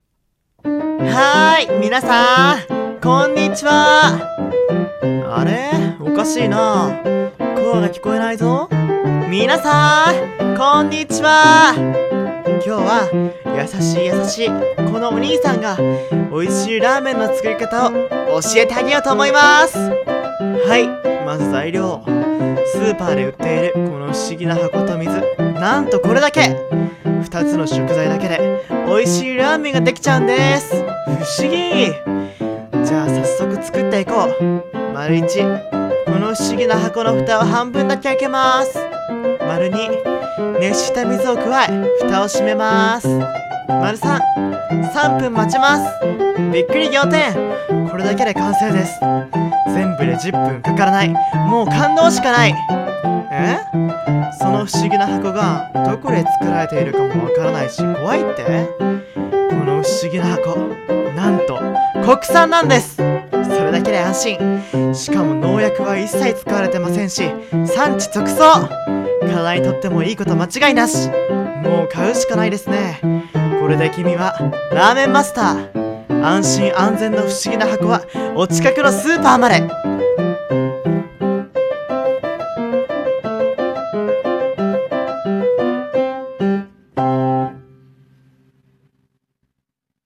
【声劇】美味しいラーメンの作り方